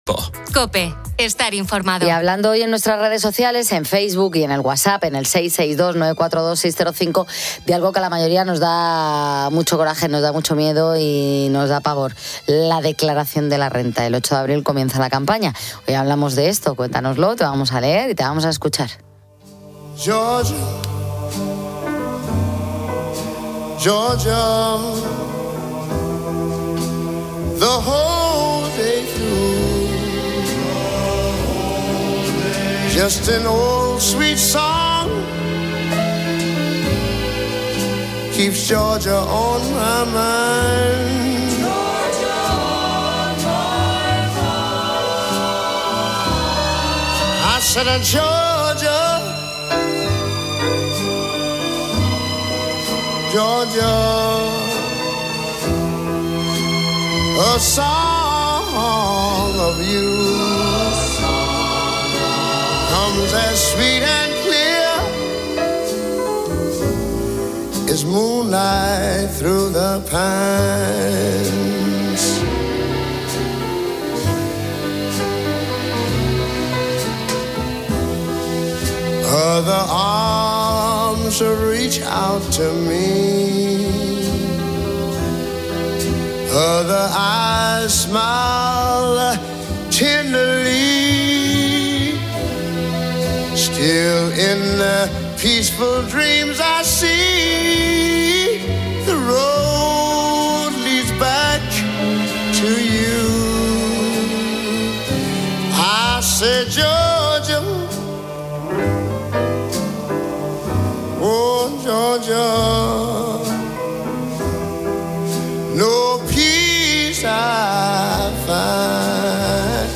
La campaña de la Renta arranca el 8 de abril y los oyentes de 'Poniendo las Calles' comparten en COPE su hartazgo y sus dudas sobre el borrador de Hacienda